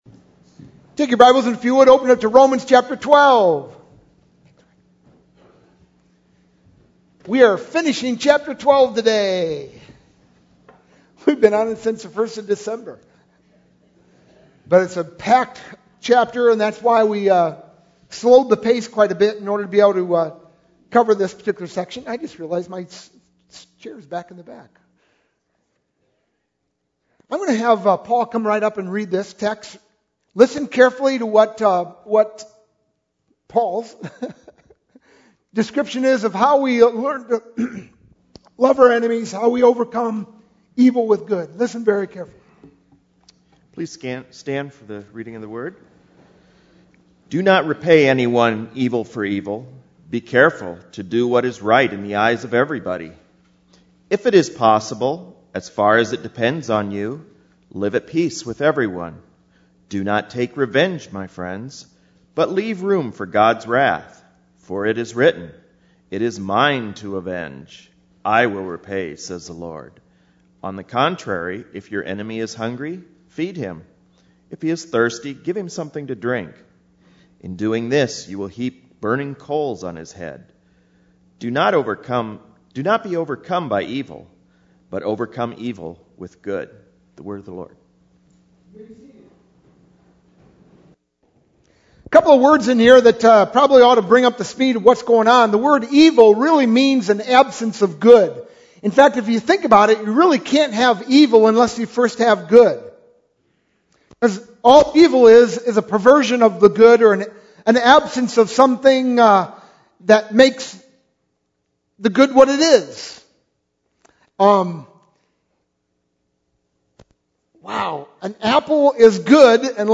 sermon-1-8-12.mp3